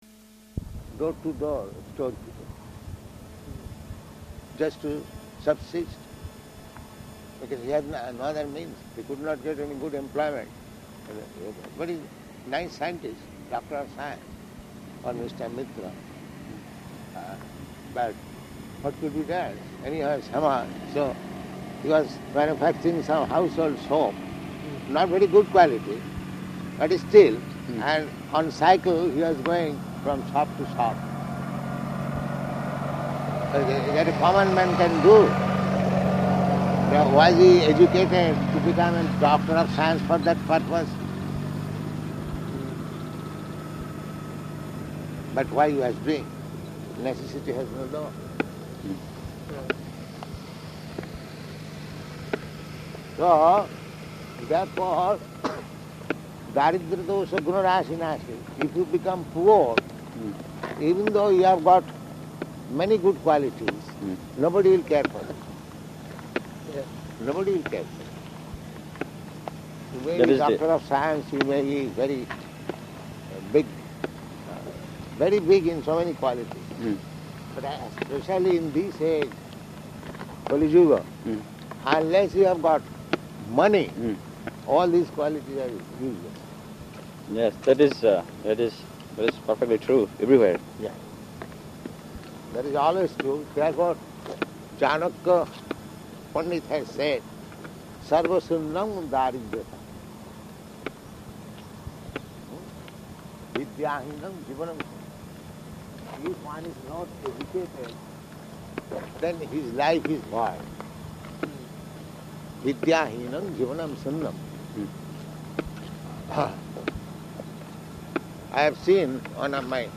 Type: Walk
Location: Los Angeles